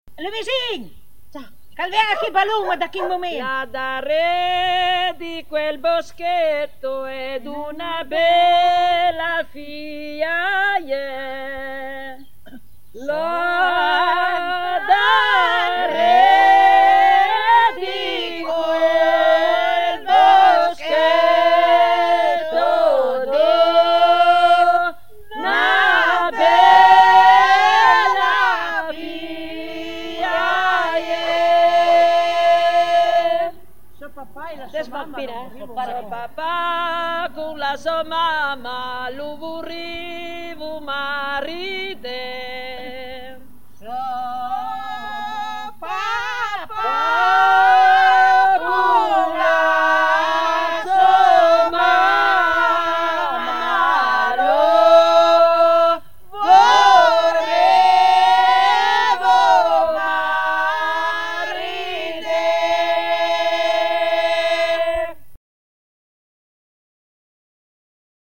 Fior di tomba / [registrata a Vettigné, Santhià (VC), nel 1970]